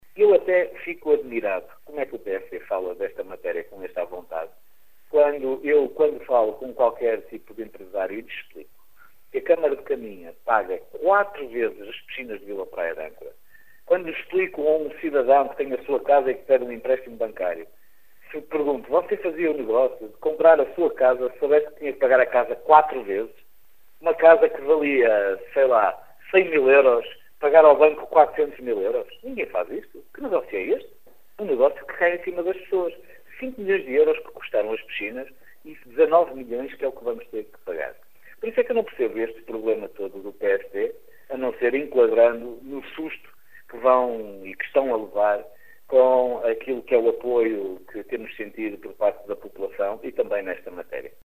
Em declarações à Rádio Caminha, Miguel Alves diz não entender as críticas da oposição em relação a uma obra “completamente ruinosa” para o concelho que, no final, vai custar 19 milhões de euros, ou seja, quatro vezes mais do que o valor inicial.